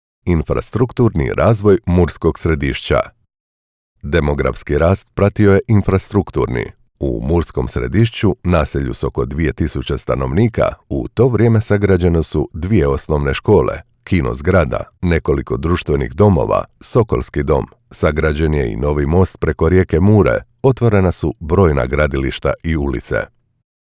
Documentaries
I am a professional Croatian voice-over artist, speaker, narrator and producer with over 20 years of experience with my own studio.
Middle-Aged
BassDeep
WarmAuthoritativeConversationalCorporateFriendly